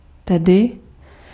Ääntäminen
IPA: /soː/